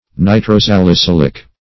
Search Result for " nitrosalicylic" : The Collaborative International Dictionary of English v.0.48: Nitrosalicylic \Ni`tro*sal`i*cyl"ic\, a. [Nitro- + salicylic.]